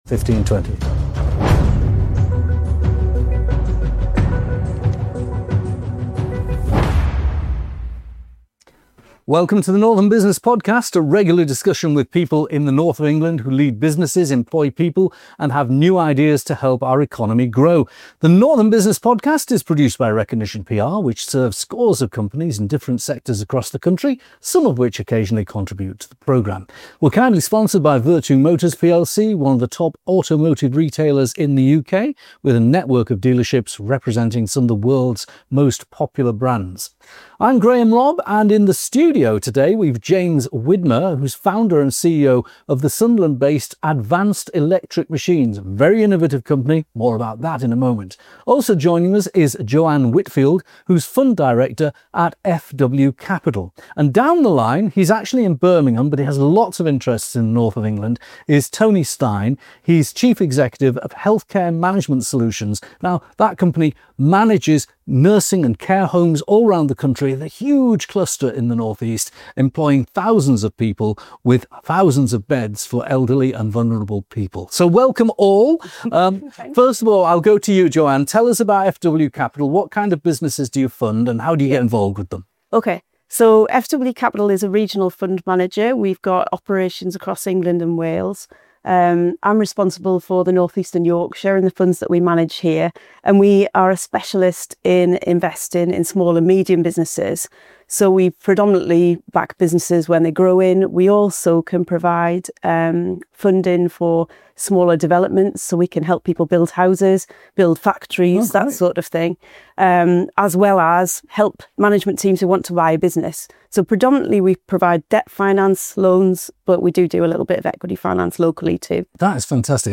Welcome to the Northern Business Podcast, a regular discussion with people in the North of England who lead businesses, employ people and have new ideas to help our economy grow.